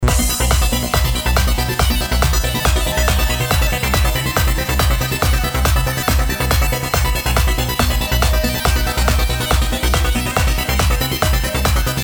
Bandoneon Bass Left Hand Accordion Concertina Double Reed Musette Accordion Single Reed Bassoon Accordion Single Reed Musette Accordion Single Reed Piccolo Accordion Steirische Harmonika (Bonus) Я почти половину из них в этом треке заюзал.
звук очень жирный
в начеле звук отжатия кнопок не превычно было слушать